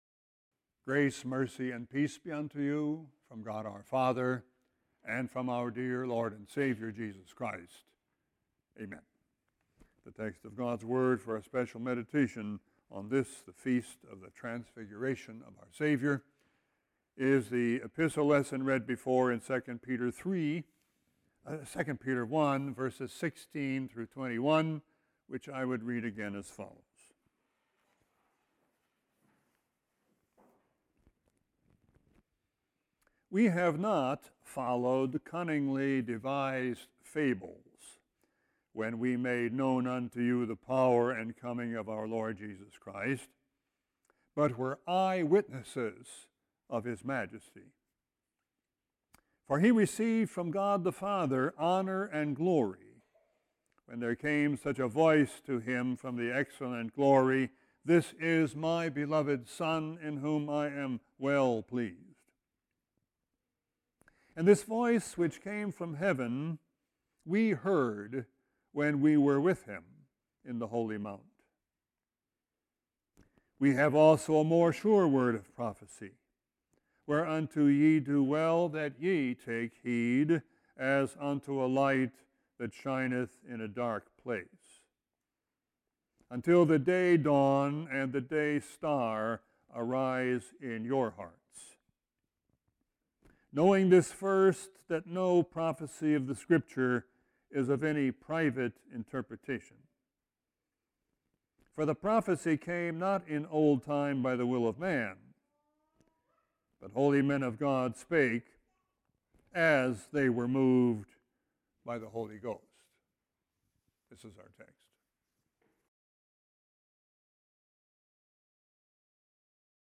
Sermon 1-21-18.mp3